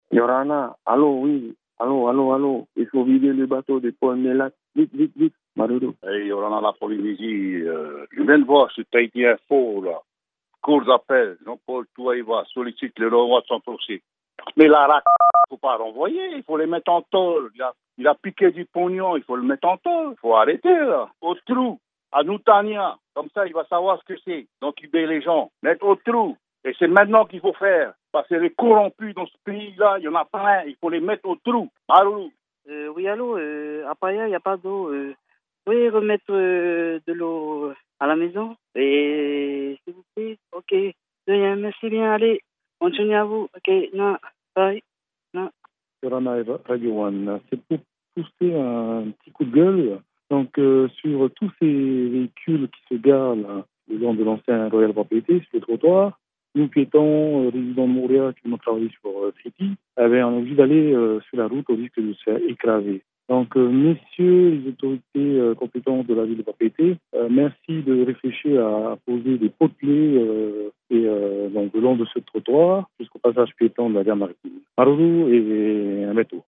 Répondeur de 12:00 le 28/12/16